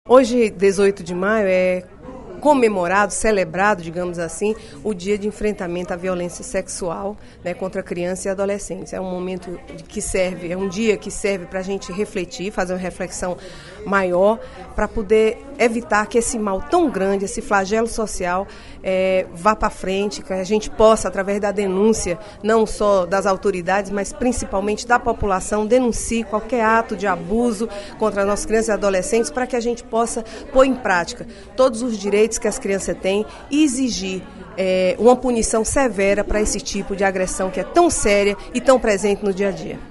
Na sessão plenária da Assembleia Legislativa desta sexta-feira (18/05), a deputada Inês Arruda (PMDB) destacou o Dia Nacional de Combate ao Abuso e Exploração Sexual de Crianças e Adolescentes, celebrado nesta sexta-feira.